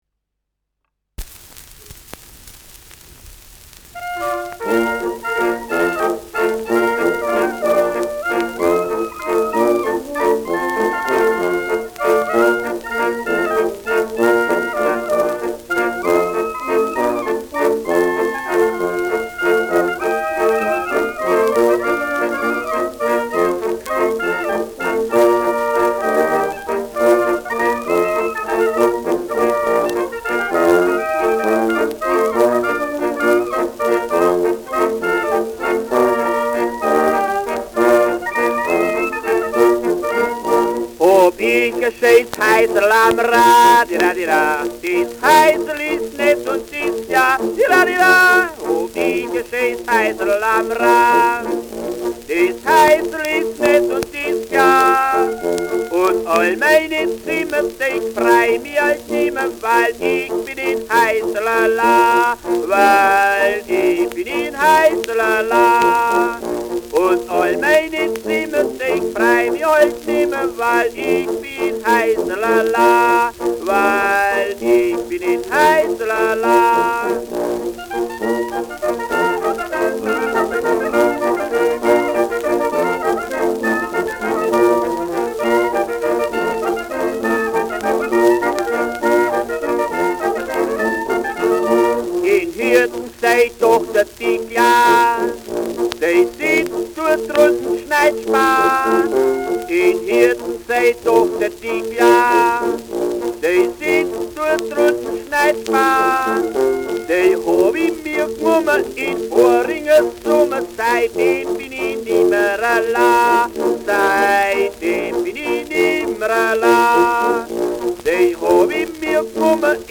Schellackplatte
leichtes Rauschen
[Nürnberg] (Aufnahmeort)